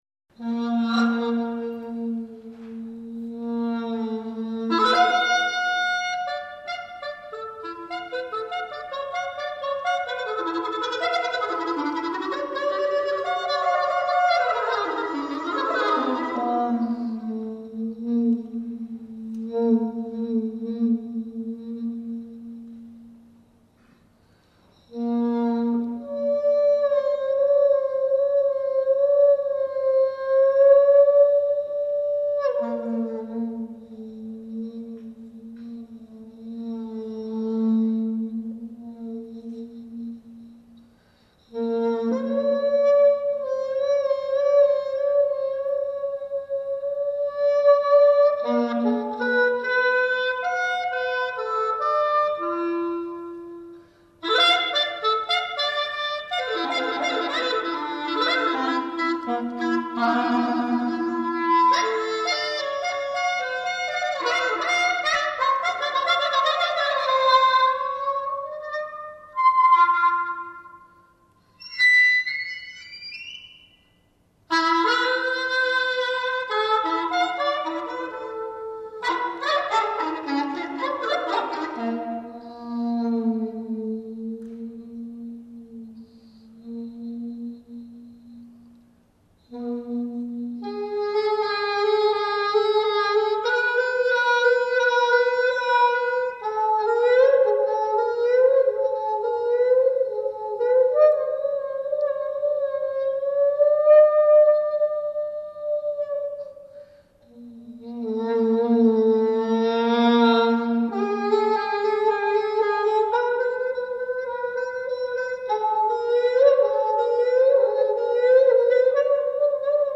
clarinet solo